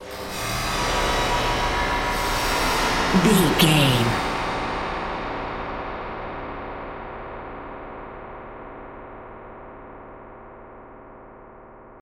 Torture Stinger.
Atonal
scary
ominous
dark
suspense
haunting
eerie
synthesiser
ambience
pads